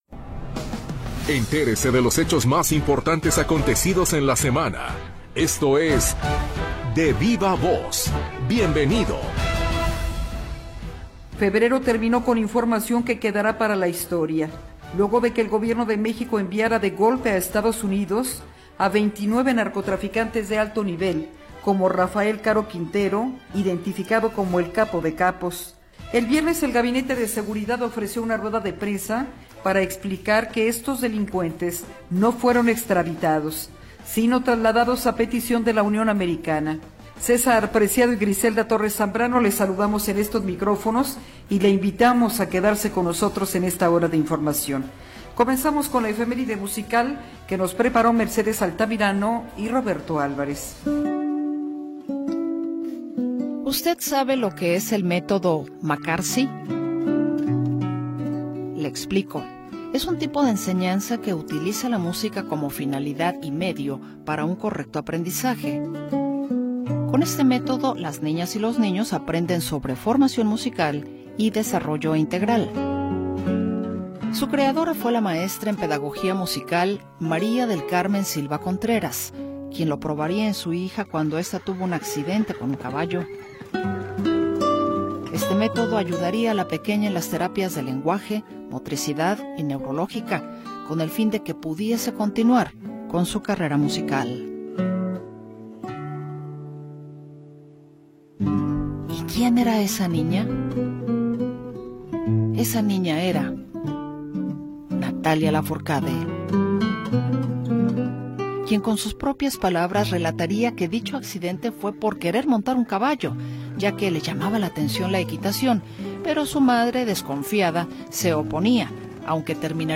Lo mejor de las entrevistas de la semana en Radio Metrópoli. Programa transmitido el 2 de Marzo de 2025.